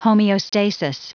Prononciation du mot homeostasis en anglais (fichier audio)
Prononciation du mot : homeostasis